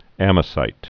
(ămə-sīt, -zīt)